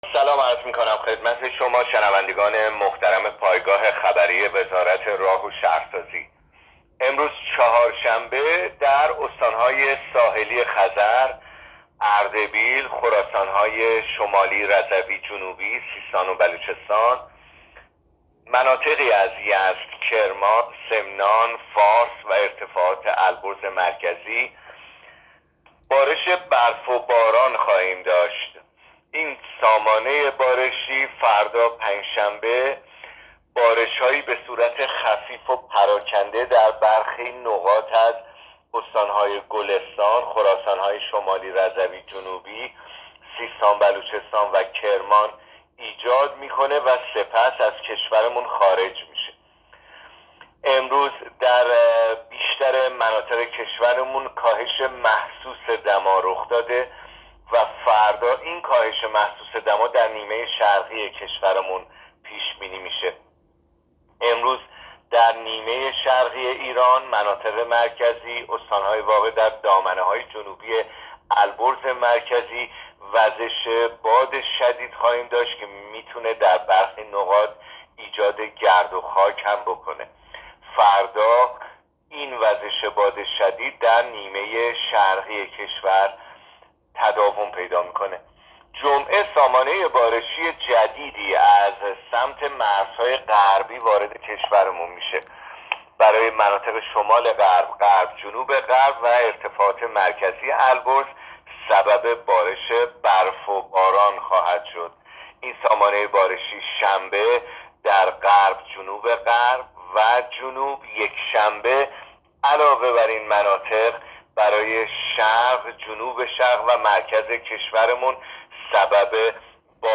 گزارش رادیو اینترنتی از آخرین وضعیت آب و هوای اول بهمن؛